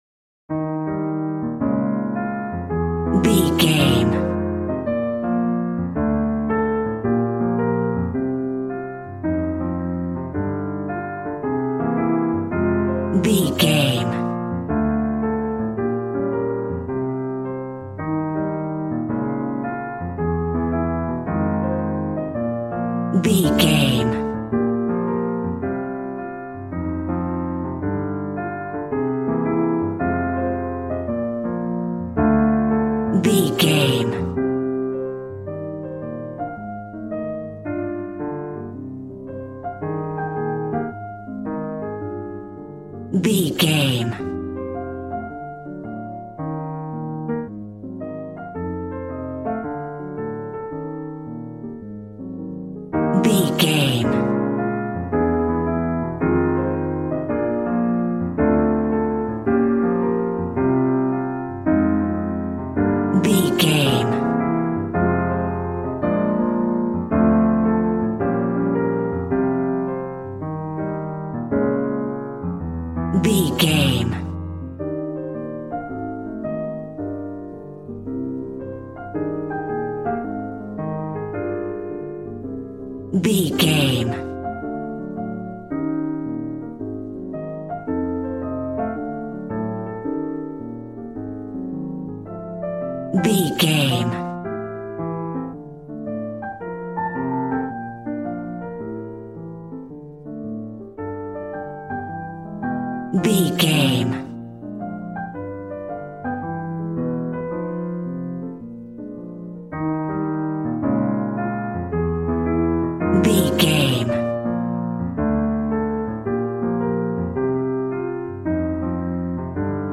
Smooth jazz piano mixed with jazz bass and cool jazz drums.,
Aeolian/Minor
cool
piano